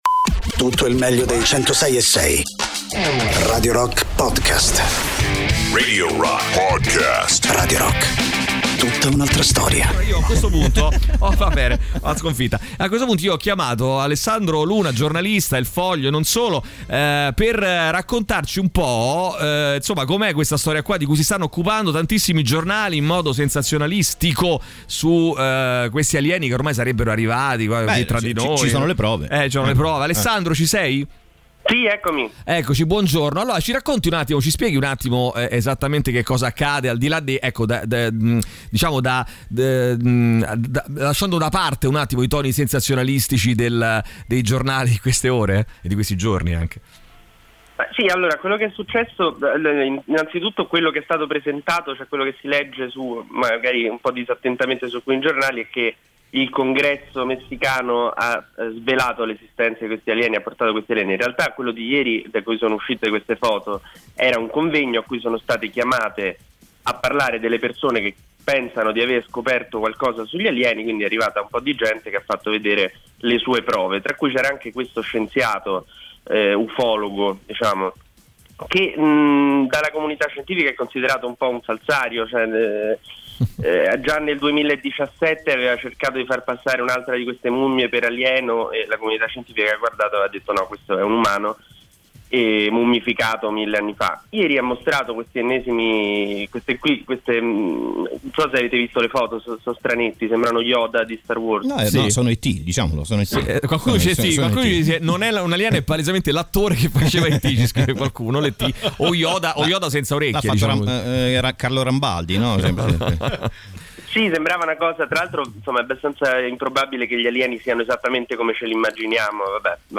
Interviste
in collegamento telefonico